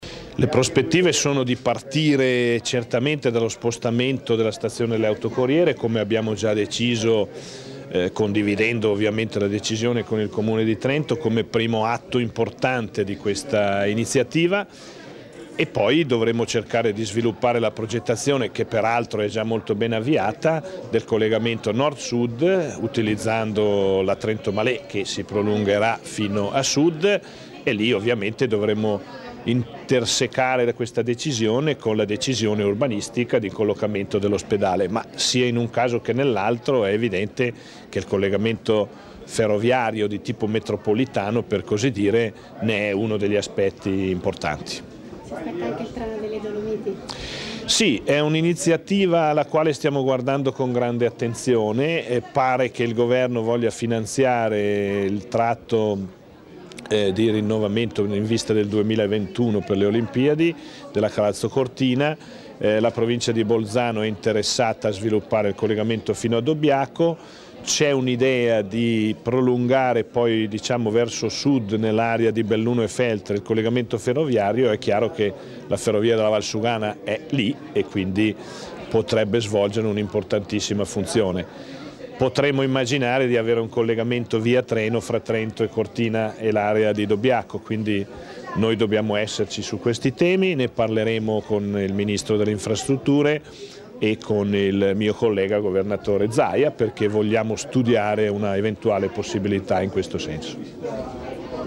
int_Ugo_Rossi.mp3